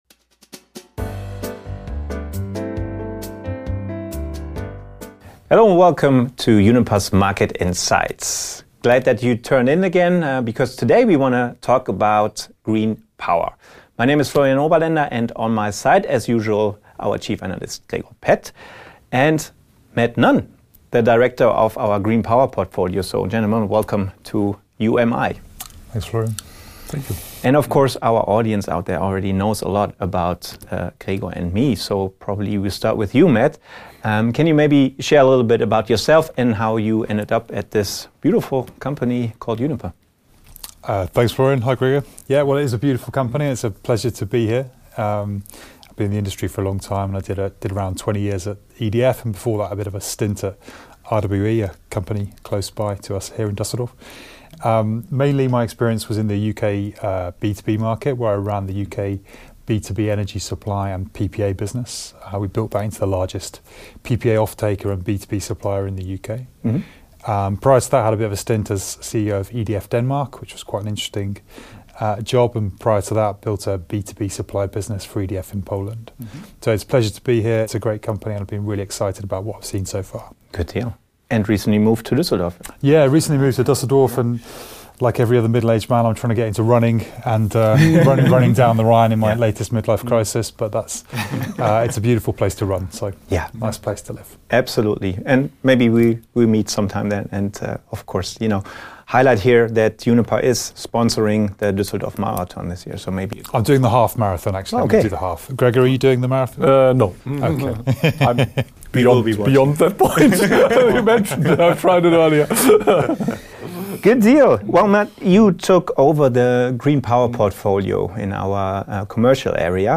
zu Gast im Studio